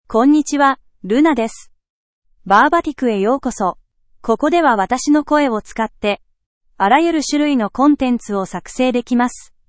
LunaFemale Japanese AI voice
Luna is a female AI voice for Japanese (Japan).
Voice sample
Listen to Luna's female Japanese voice.
Female
Luna delivers clear pronunciation with authentic Japan Japanese intonation, making your content sound professionally produced.